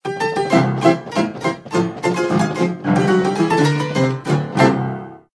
Piano_Tuna.ogg